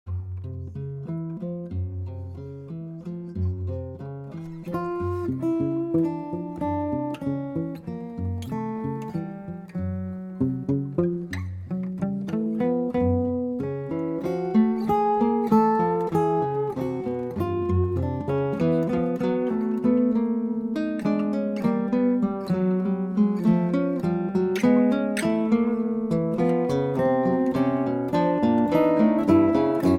Two Master Guitarists + 18 tracks = Acoustic Improv Heaven